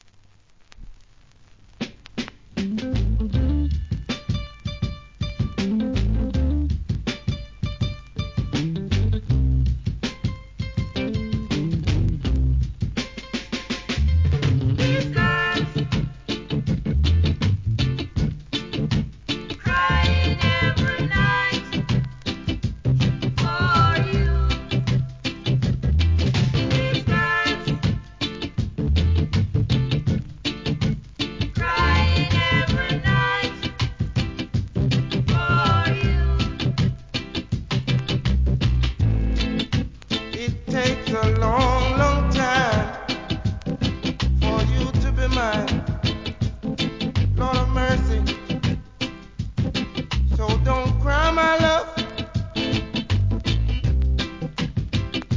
REGGAE
お馴染みギーター・イントロ